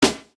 You’ll want the ‘fake tail’ portion of the sound to fade out naturally, but the reversed transient to be completely silent.